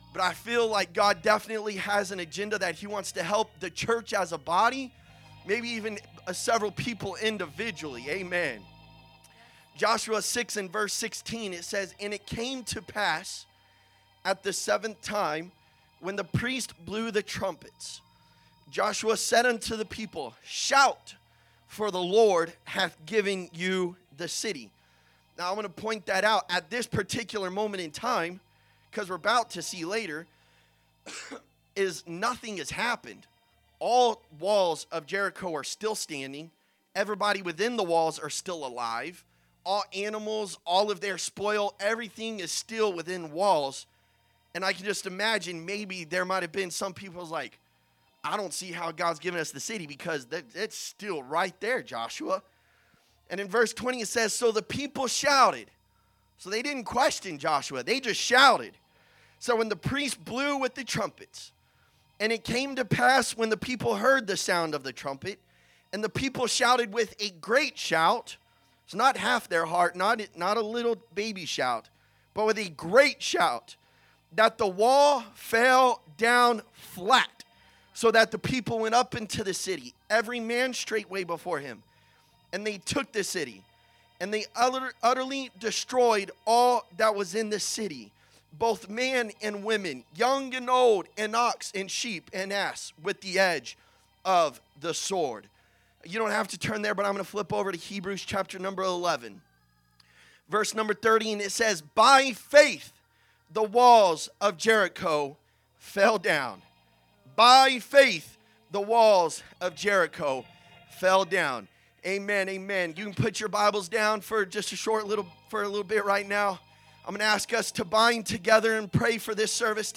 11/16/2025 Sunday Evening Service